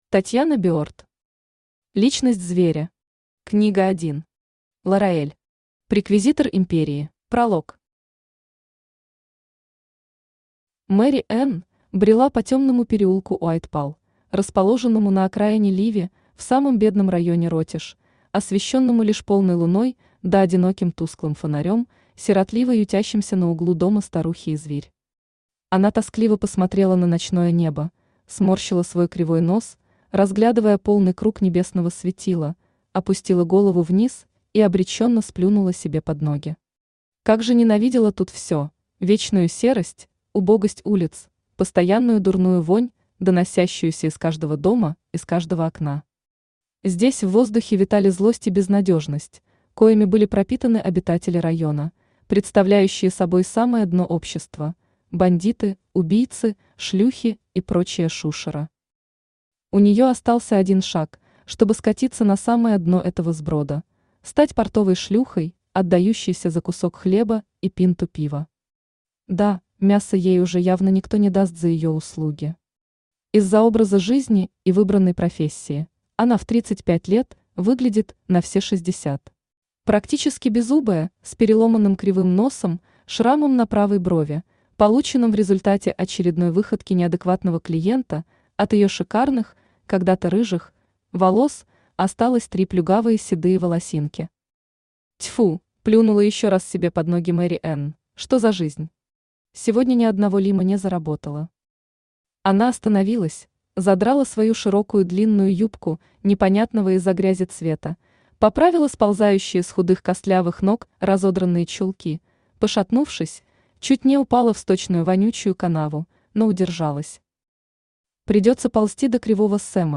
Аудиокнига Личность зверя. Книга 1. Лараэль. Преквизитор империи | Библиотека аудиокниг
Aудиокнига Личность зверя. Книга 1. Лараэль. Преквизитор империи Автор Татьяна Berd Читает аудиокнигу Авточтец ЛитРес.